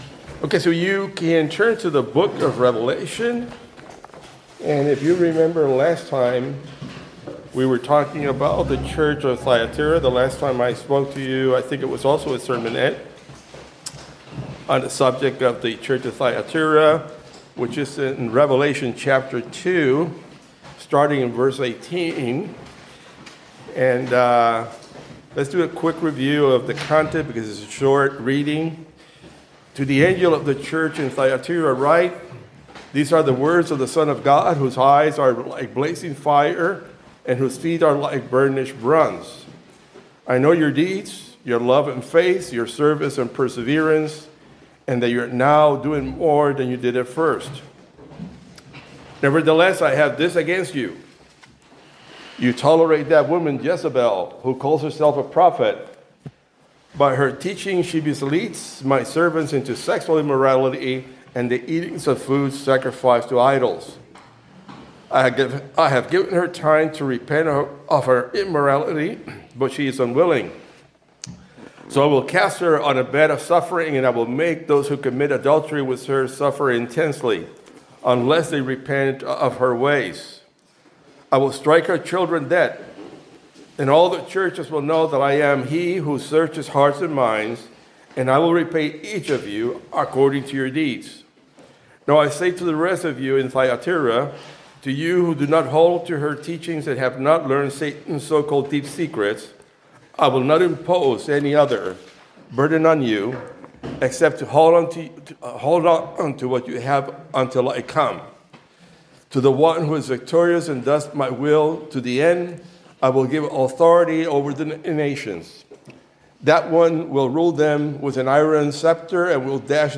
The sermonette discusses the message to the Church of Thyatira in Revelation, highlighting how Christians there faced pressure to compromise their faith by participating in pagan practices for economic security. It draws parallels to modern challenges, warning that believers may again be tested to choose between faithfulness and material well-being, but encourages steadfastness, assuring that God will support and reward those who remain faithful despite hardships.